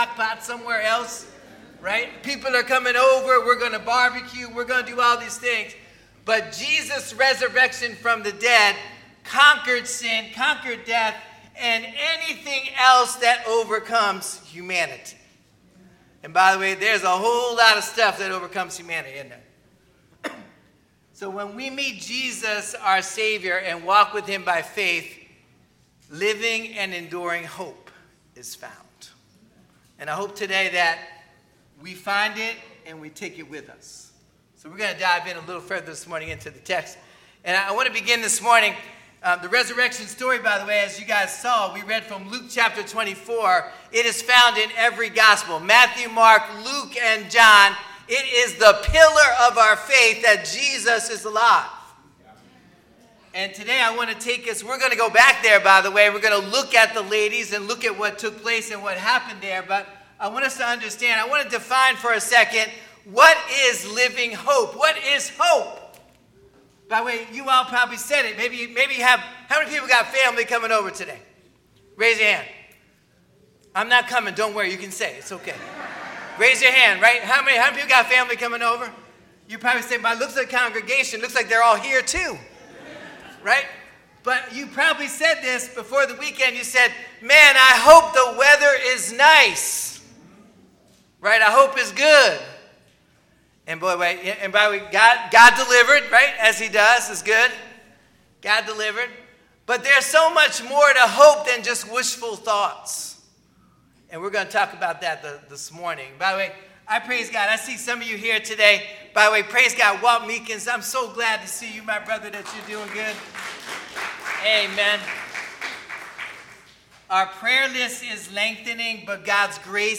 Sermons | First Baptist Church of Willingboro, NJ